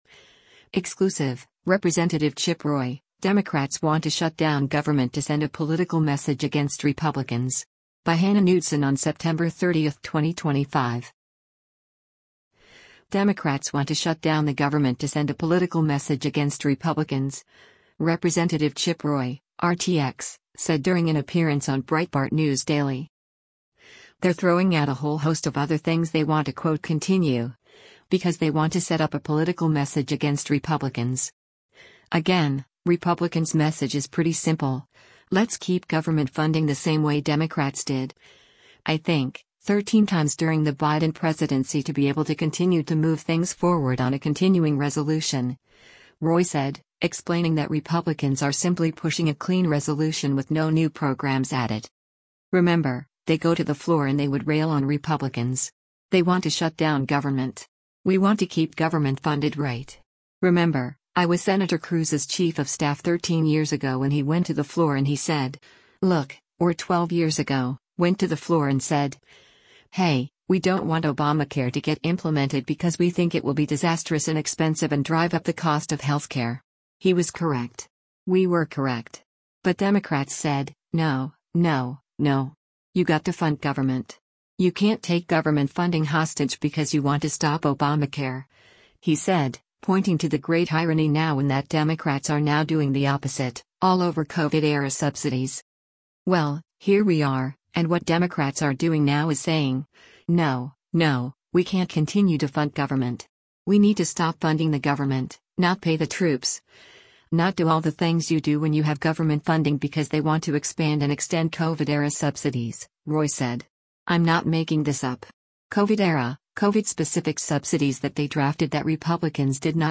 Democrats want to shut down the government to send a “political message against Republicans,” Rep. Chip Roy (R-TX) said during an appearance on Breitbart News Daily.
Breitbart News Daily airs on SiriusXM Patriot 125 from 6:00 a.m. to 9:00 a.m. Eastern.